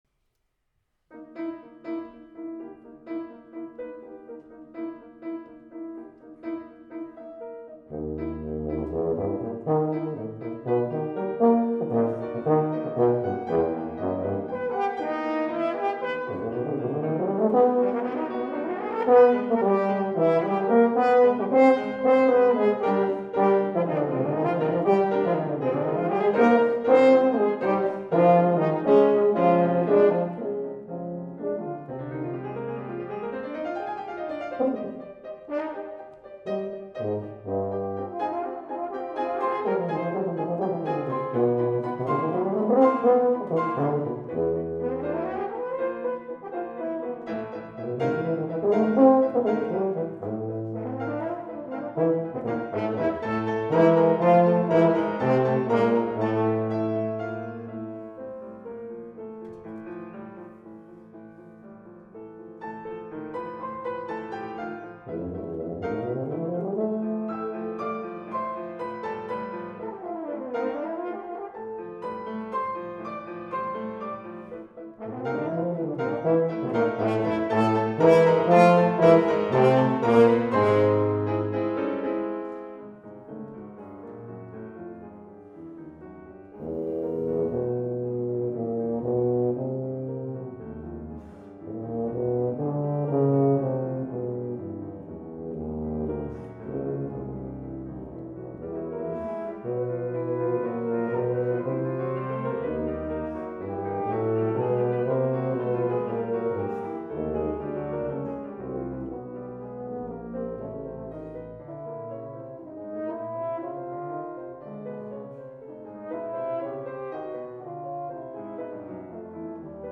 Recent favorite, unedited solo and chamber music performances
Elizabeth Raum, Color Code for horn, tuba, and piano, mvt. 1, Eastman School of Music Hatch Recital Hall, 2012